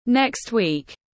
Tuần sau tiếng anh gọi là next week, phiên âm tiếng anh đọc là /nekst wiːk/
Next week /nekst wiːk/